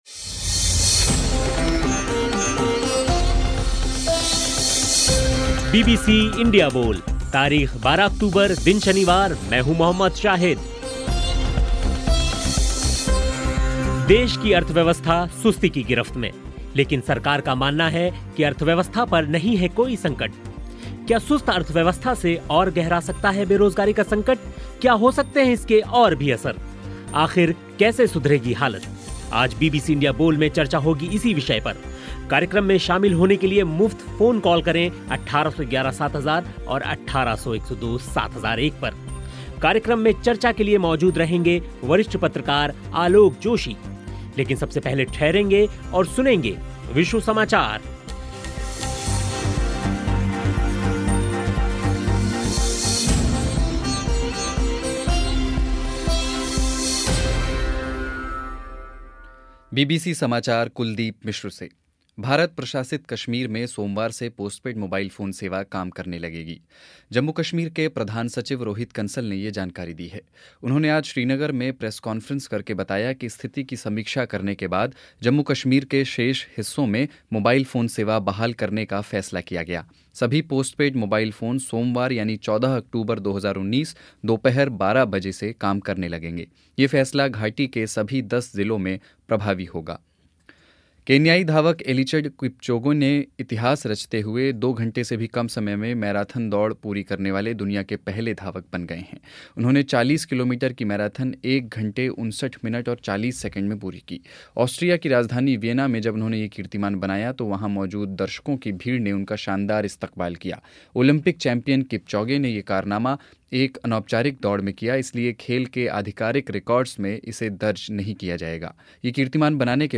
लेकिन सबसे पहले विश्व समाचार सुनिए.